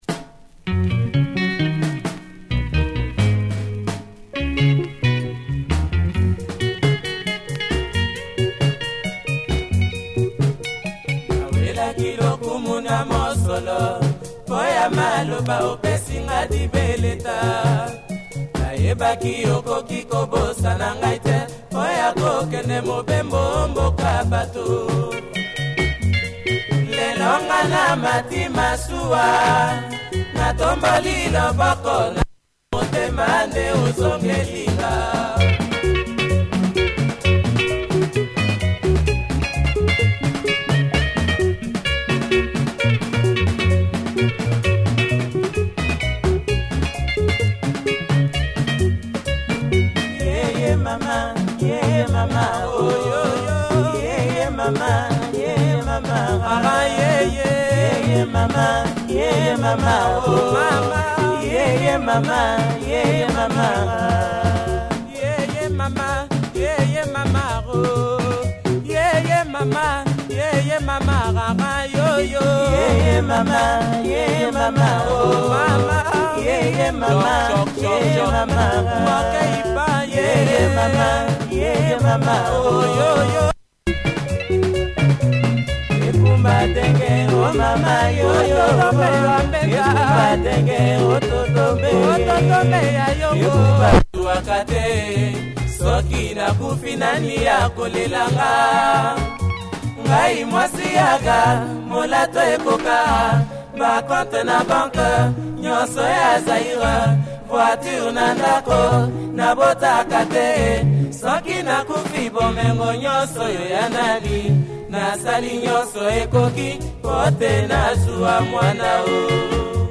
nice drums! https